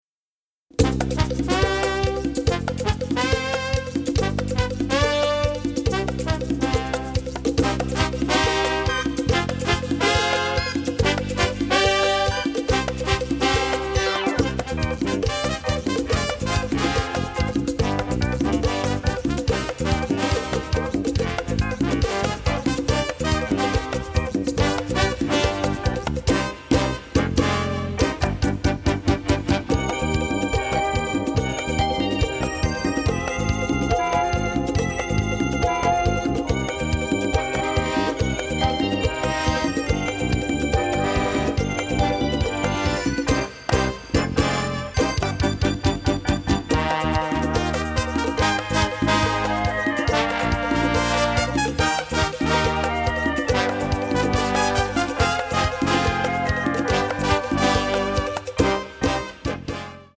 mastered from the original tapes